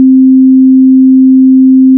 Middle C - 261.63 Hz, pure tone, fundamental
The pitch sensation for all 3 of these sounds should be that of middle C. Even though the last sound does not even contain the frequency associated with middle C (261.63 Hz) the sensation should still be that of middle C. The 4th partial (harmonic) is a sine wave at 4 x 261.63 Hz = 1,046.5 Hz.